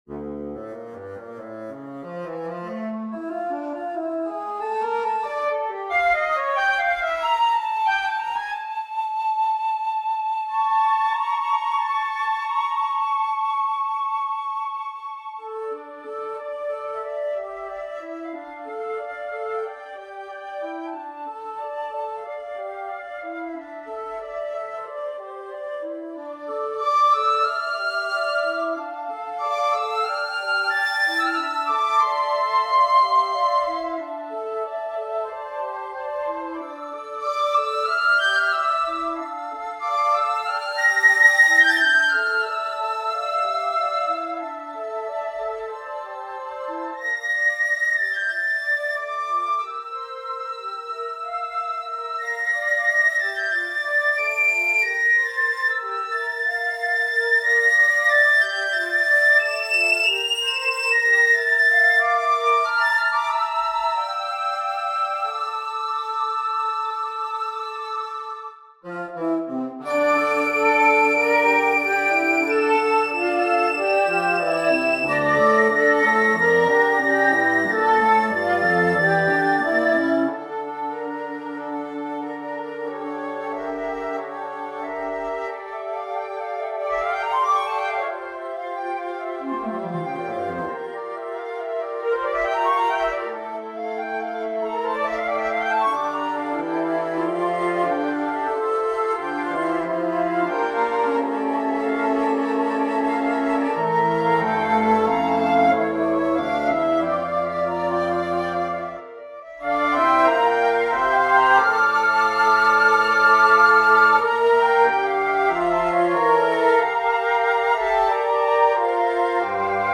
Scored for piccolo, 4 C flutes, alto, bass, and contrabass.
simulation